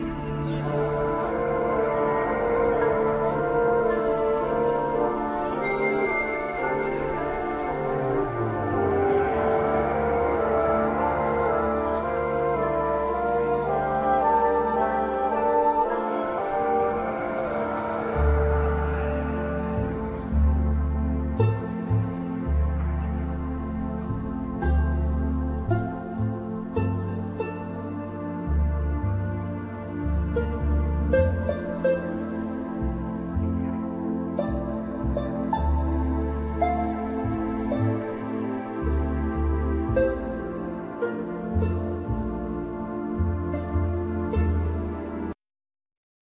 Banjo,Guitars,Piano
Double Bass
Vocals
Violin
Accordion
Drums